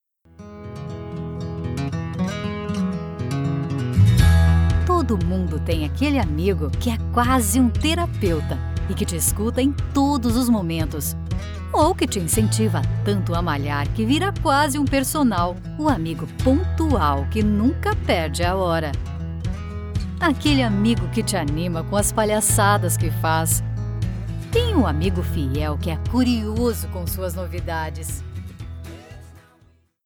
Commercial Demo
My professional and well-equipped Home Studio provides me with the ability to offer a QUICK turnaround to clients around the world, whenever needed.
✦Warm, soft, low-pitched, friendly, excellent diction, trusted voice.